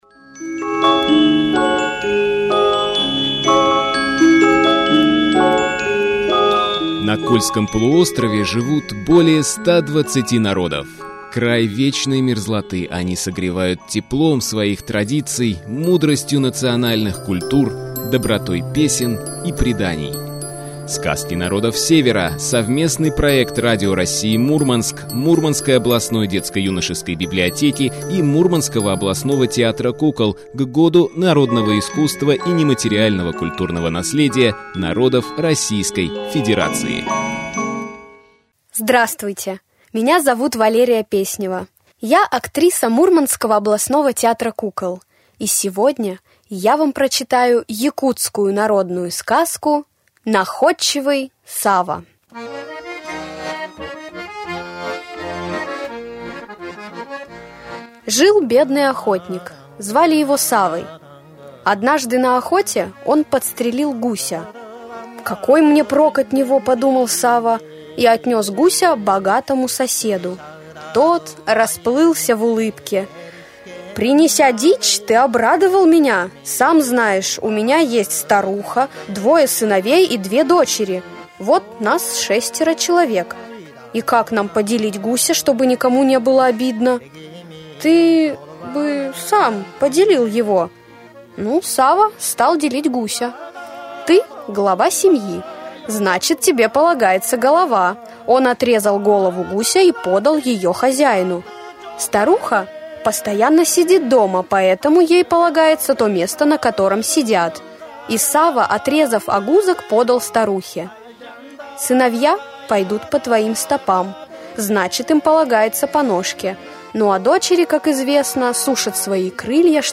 В эфире «Радио России – Мурманск» артисты театра кукол читают для радиослушателей сказки народов, которые проживают на территории нашей многонациональной Родины.
Якутскую народную сказку